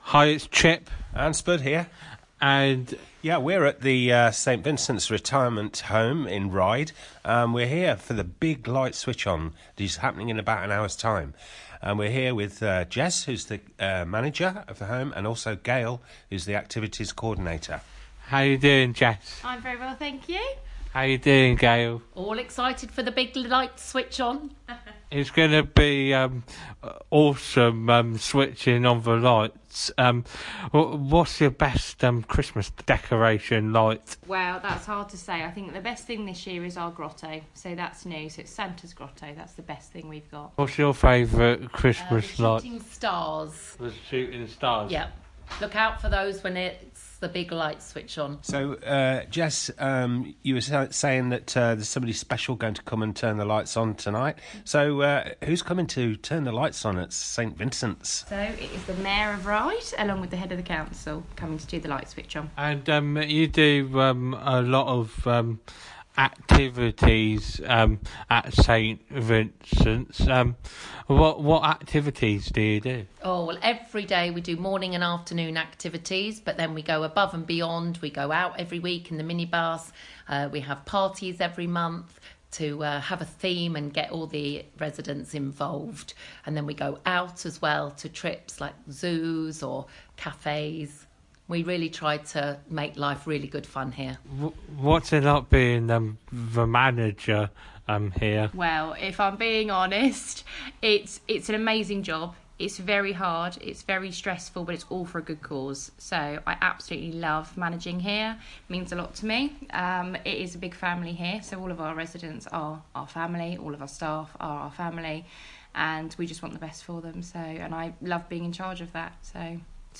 Interview
St Vincents residential Home Christmas light switch on 2023